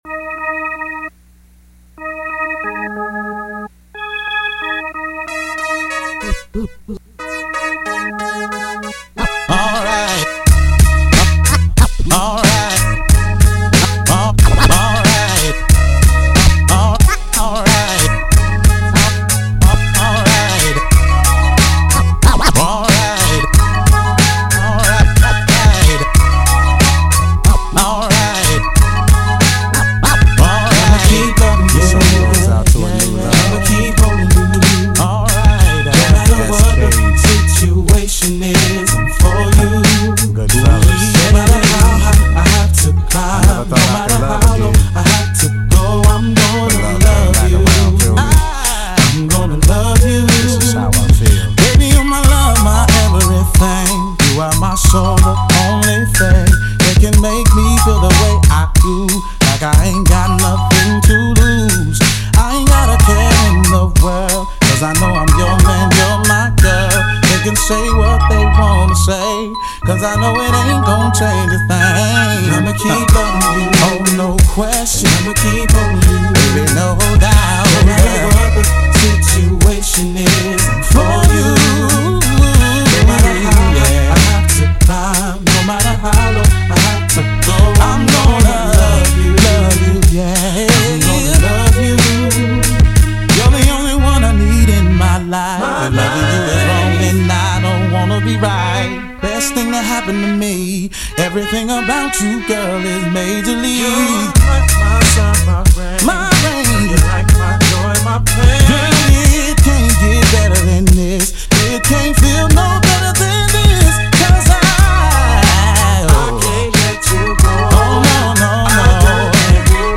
Another round of remixes